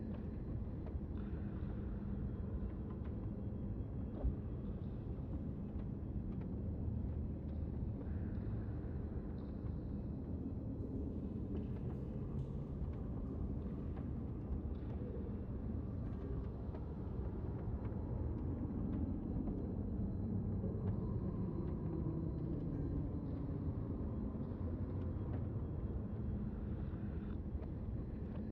Minecraft Version Minecraft Version latest Latest Release | Latest Snapshot latest / assets / minecraft / sounds / ambient / nether / nether_wastes / ambience.ogg Compare With Compare With Latest Release | Latest Snapshot
ambience.ogg